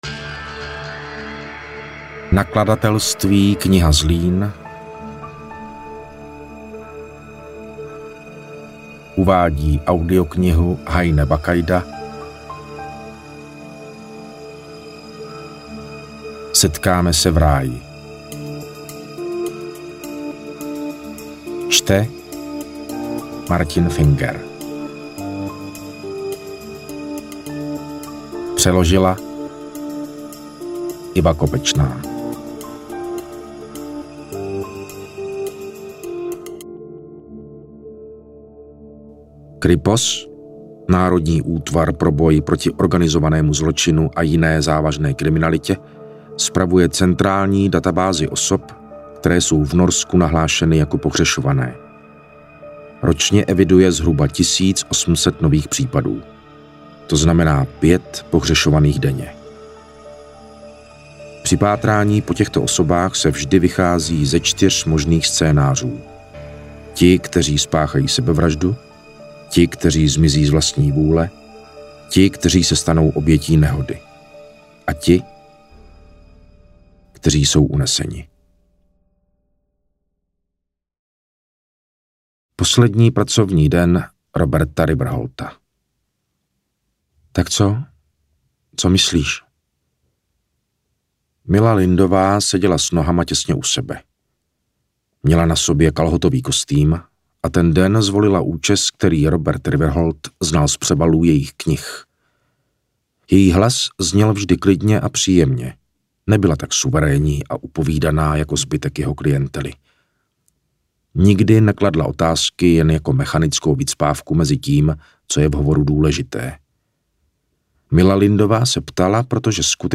Interpret:  Martin Finger
AudioKniha ke stažení, 125 x mp3, délka 12 hod. 18 min., velikost 667,6 MB, česky